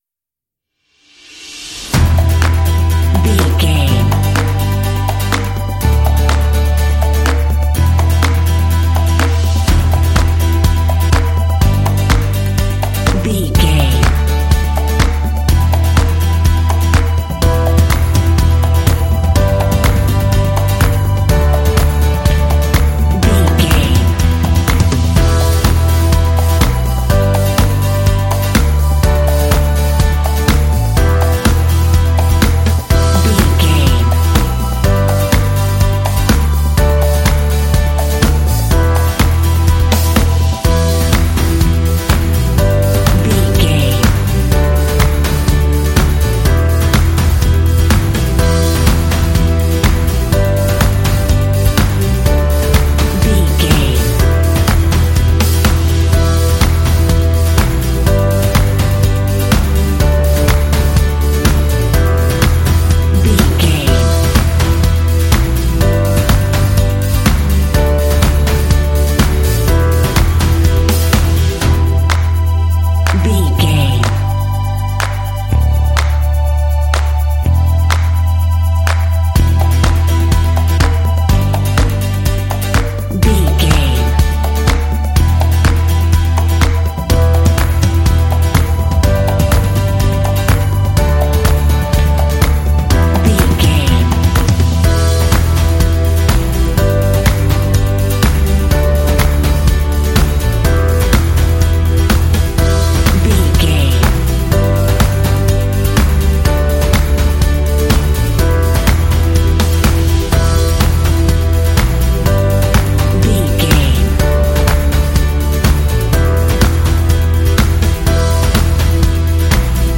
Uplifting
Aeolian/Minor
lively
cheerful
acoustic guitar
conga
percussion
drums
bass guitar
strings
synth-pop
rock
indie